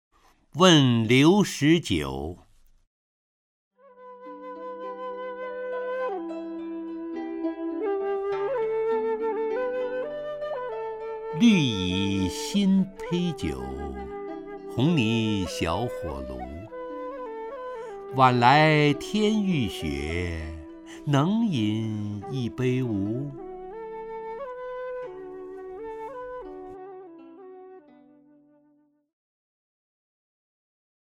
陈醇朗诵：《问刘十九》(（唐）白居易) （唐）白居易 名家朗诵欣赏陈醇 语文PLUS